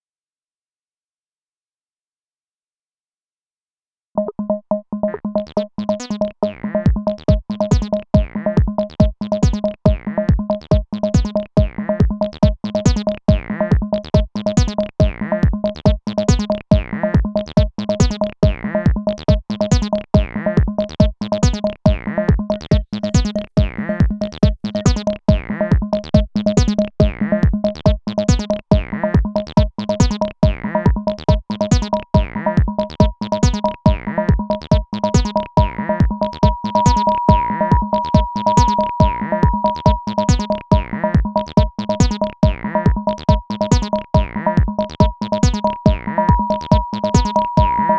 testing new setup. not what i wanna make, but techno is fun.